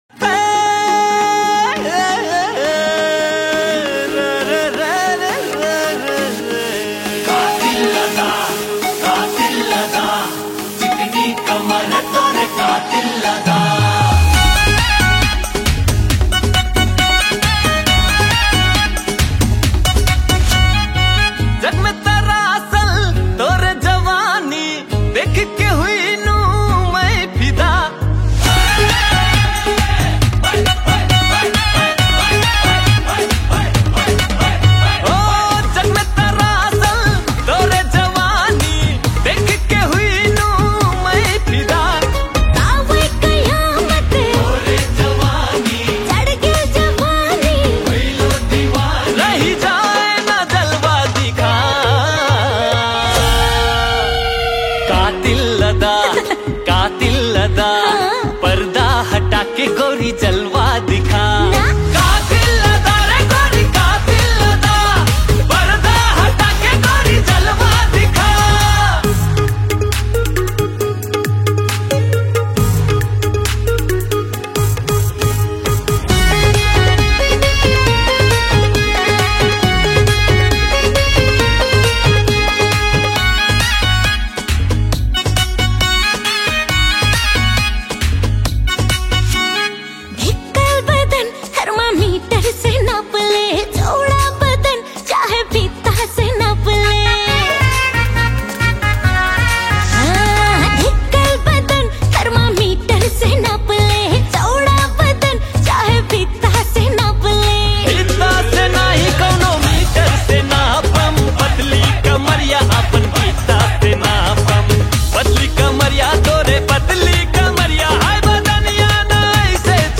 Tharu Romantic Song Tharu Item Dancing Song